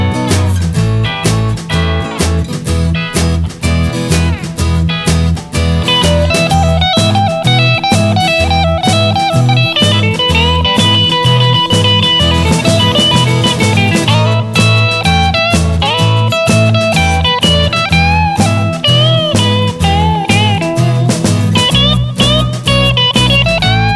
With Scream Rock 'n' Roll 2:37 Buy £1.50